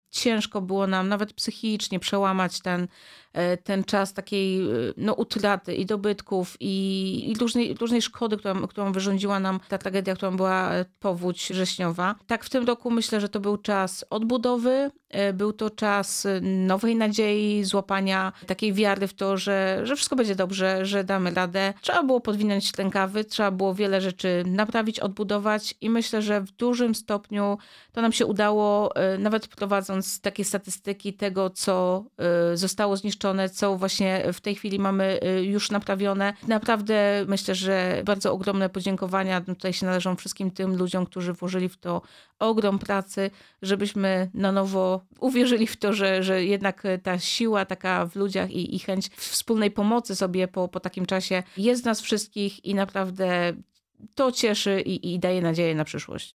Zbliżający się Sylwester, rosnąca popularność górskich wędrówek, potrzeba ochrony przyrody oraz 5. edycja akcji „Choinka dla Życia” – to główne tematy rozmowy w studiu Radia Rodzina.